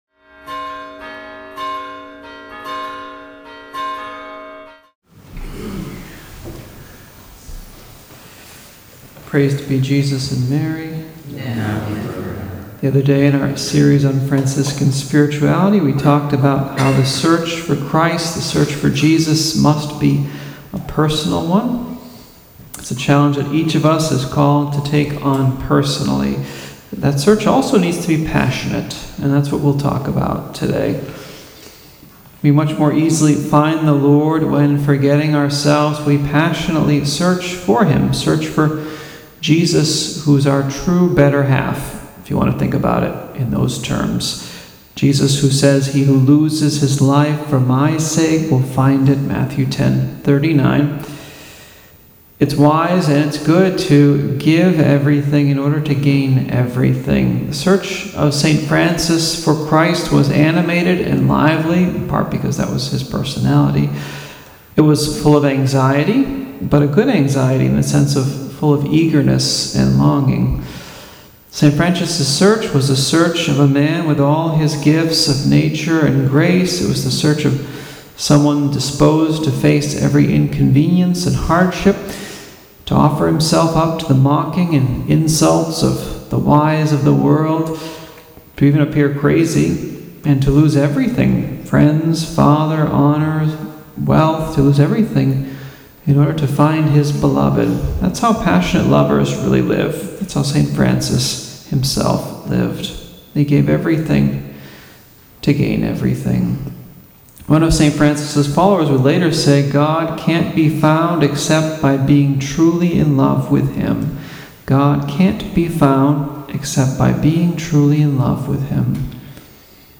Homily